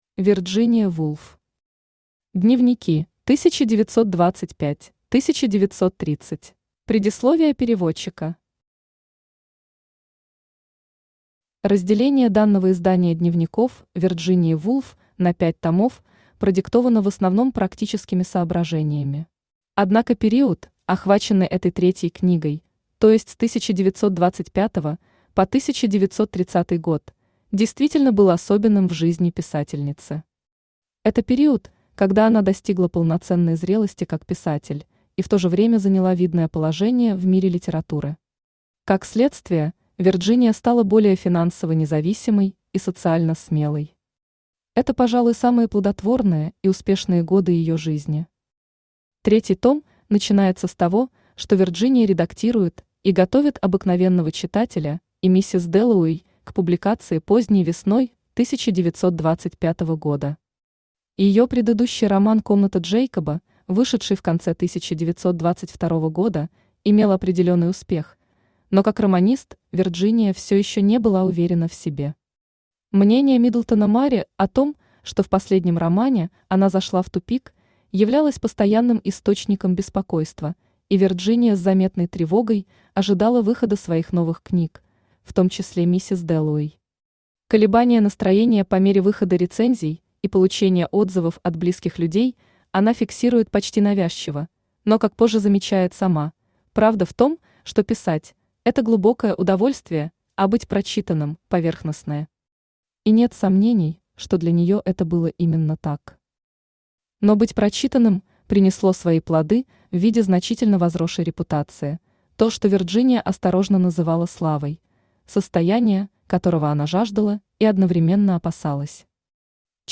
Aудиокнига Дневники: 1925–1930 Автор Вирджиния Вулф Читает аудиокнигу Авточтец ЛитРес.